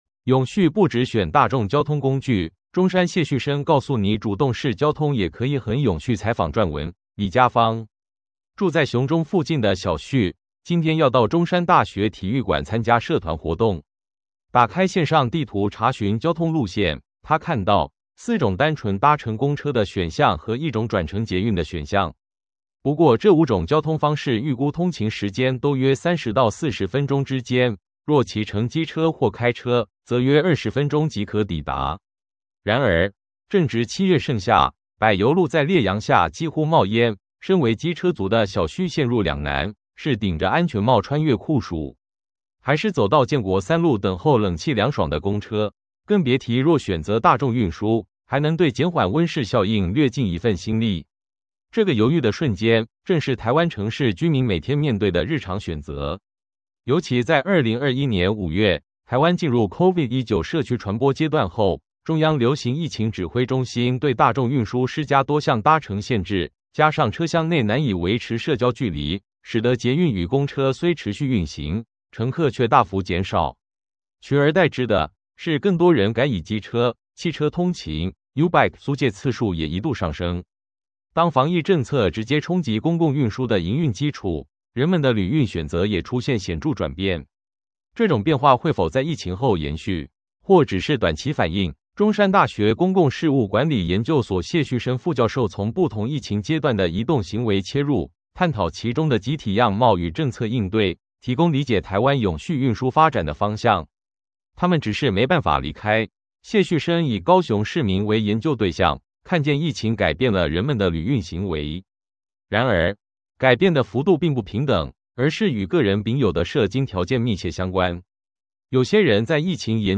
全文朗讀